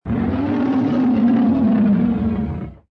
Descarga de Sonidos mp3 Gratis: roar 4.